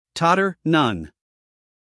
英音/ ˈtɒtə(r) / 美音/ ˈtɑːtər /